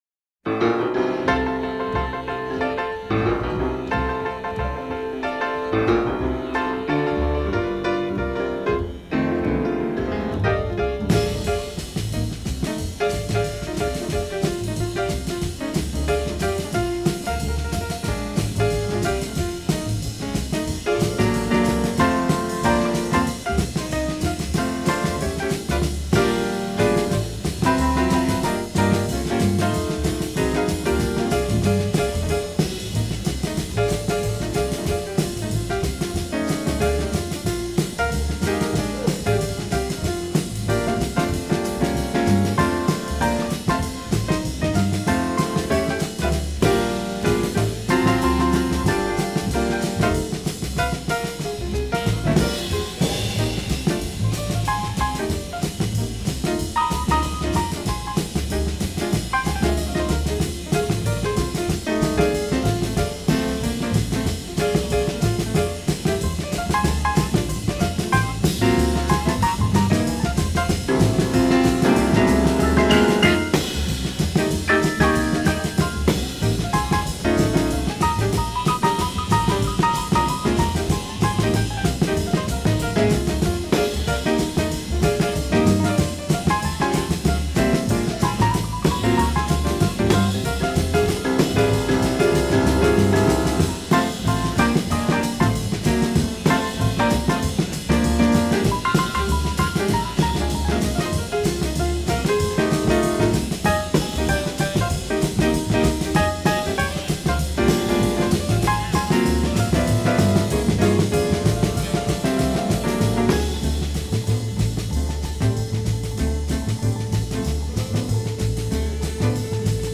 gospel underpinnings
pianist
in a live recording